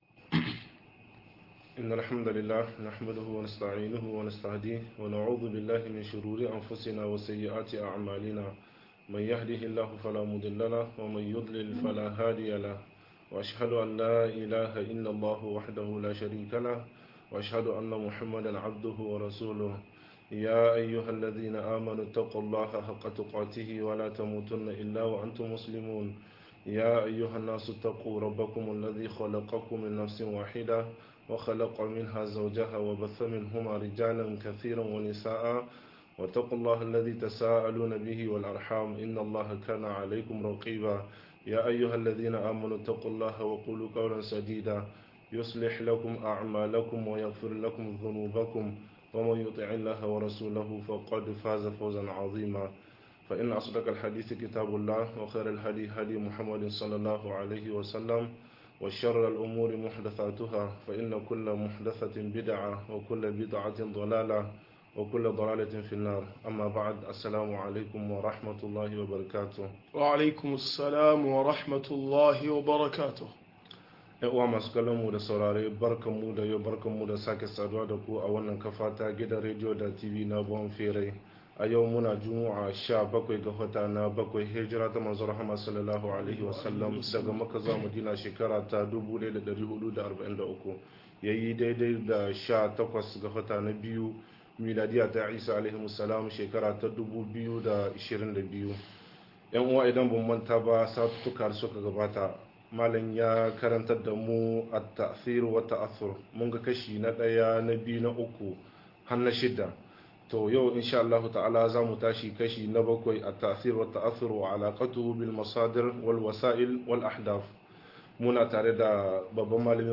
Yin tasiri da tasirantuwa-07 - MUHADARA